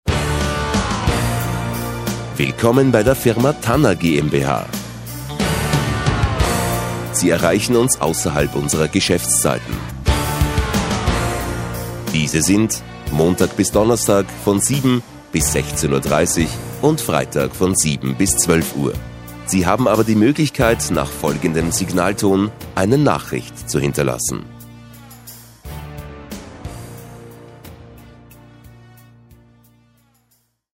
Profi-Sprecher deutsch.
Sprechprobe: Industrie (Muttersprache):
german voice over talent